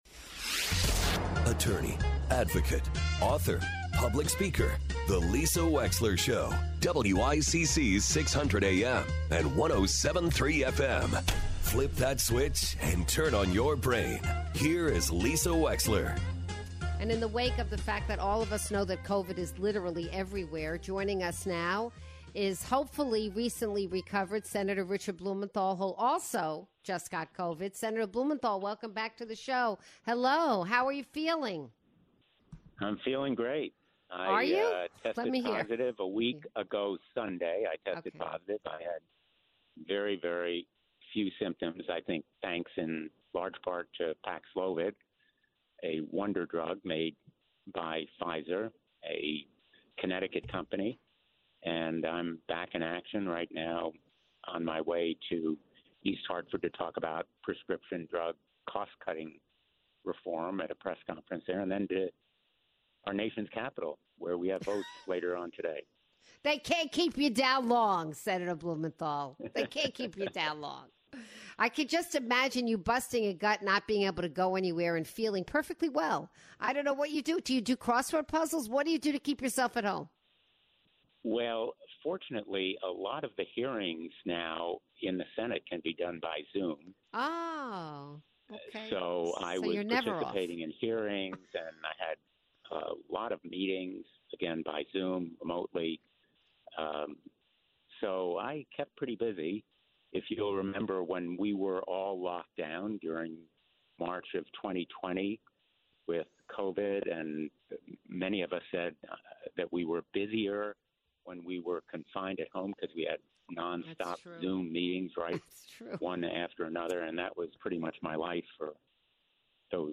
Senator Richard Blumenthal calls in to give an update on Capitol Hill.